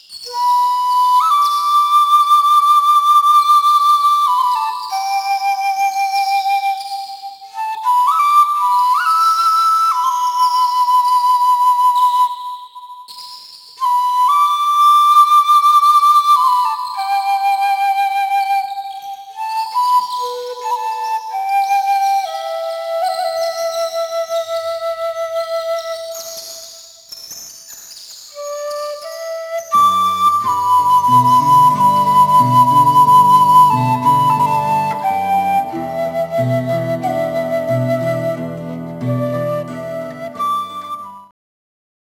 フォルクローレミュージックのバンドを組んでいます。
（インストゥルメンタル：ペルー伝承曲）